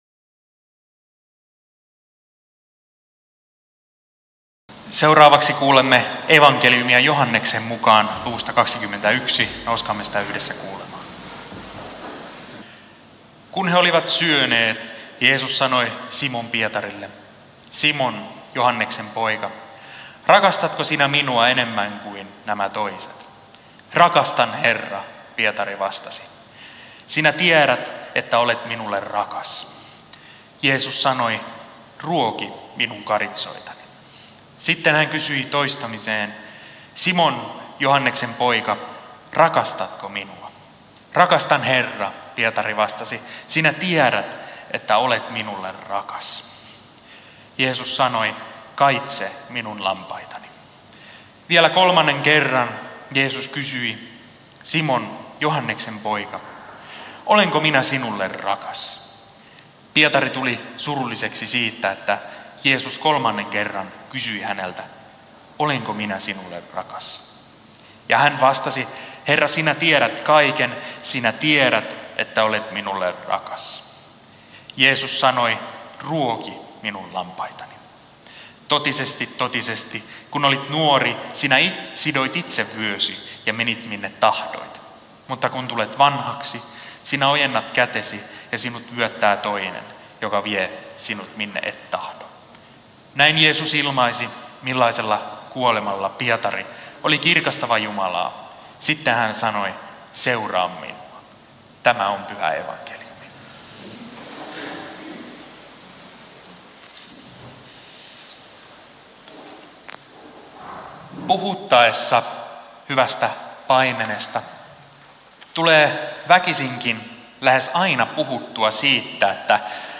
Kälviä